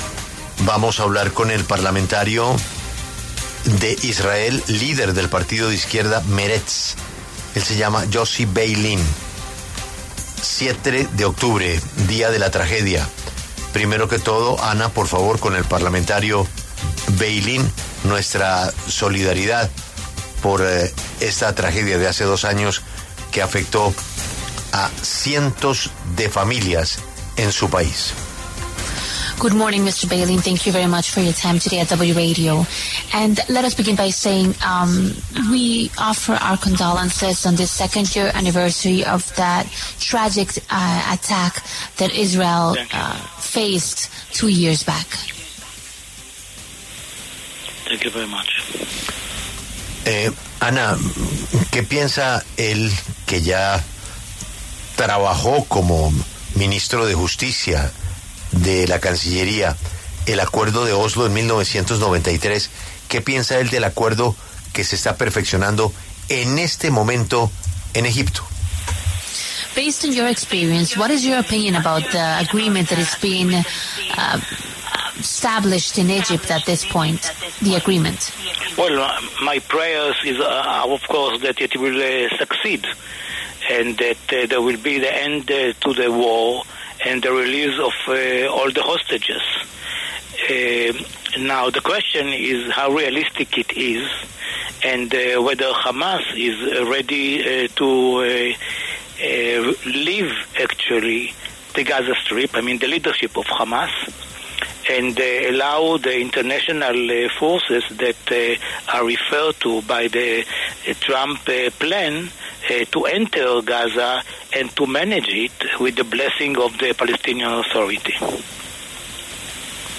Yossi Beilin, parlamentario en Knesset de Israel y líder del partido de izquierda Meretz, conversó con La W sobre las negociaciones indirectas de paz para la Franja de Gaza.